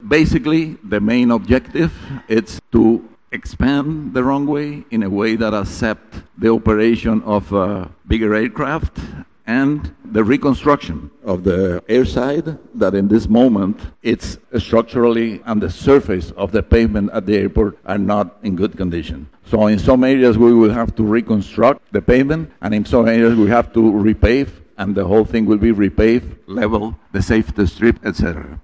A consultation took place at the Pond Hill Community Centre on Thursday, November 14th, to update the Nevisian public about the Vance W. Amory International Airport development project.